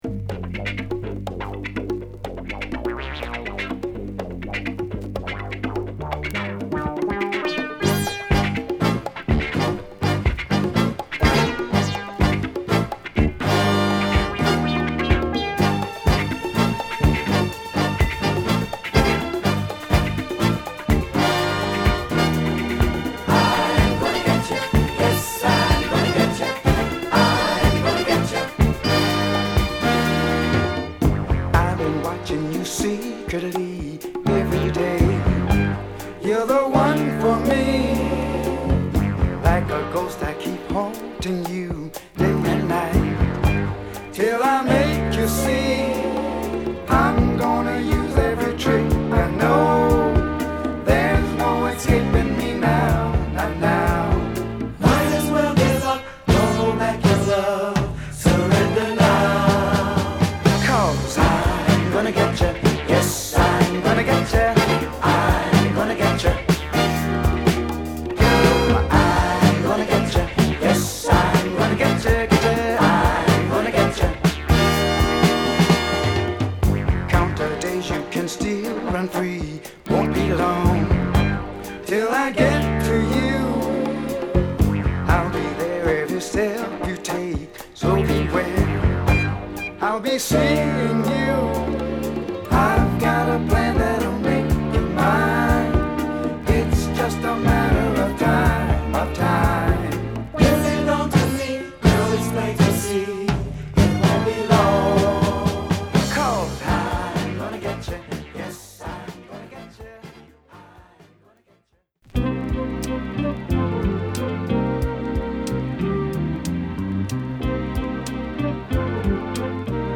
アルバム通してフィリーマナーのグッドソウルを収録！